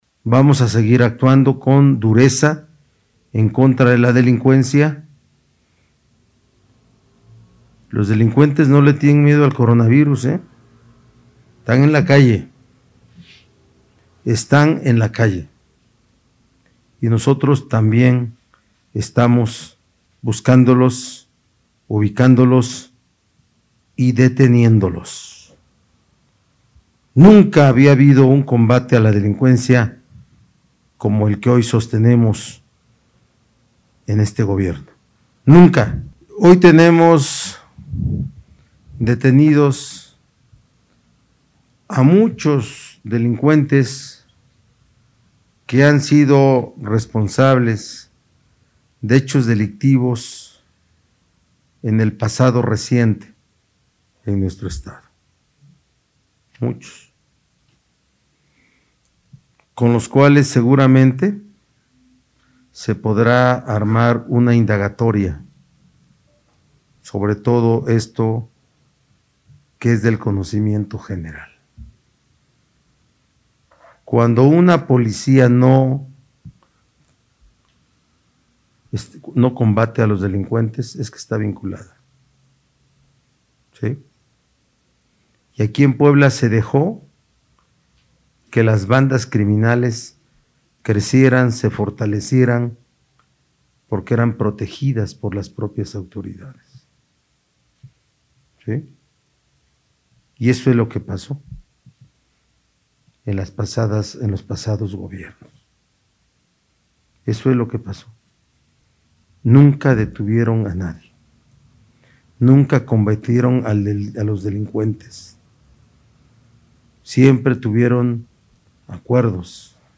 La actividad delictiva en la capital poblana disminuye significativamente gracias a la detención de los principales operadores de las bandas delincuenciales, destacó el gobernador Miguel Barbosa Huerta, durante una rueda de prensa en Casa Aguayo.